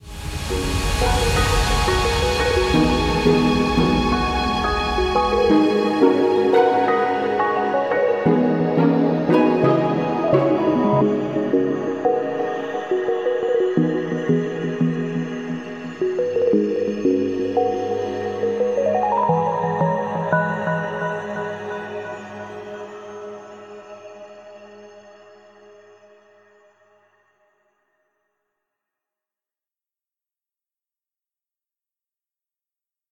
applause.ogg